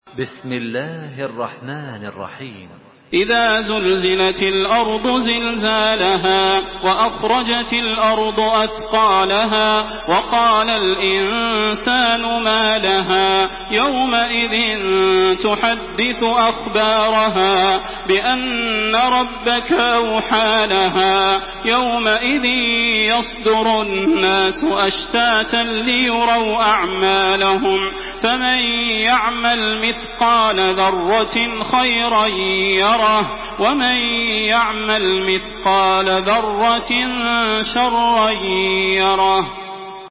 Makkah Taraweeh 1427
Murattal Hafs An Asim